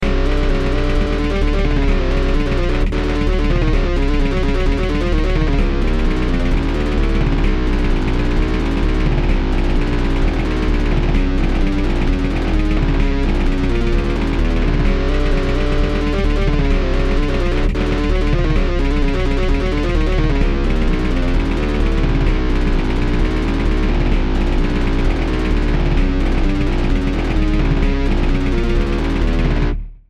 Та це просто рандомні треки створені комп’ютером) З мене такий мизикант, як з жиротандра адмін.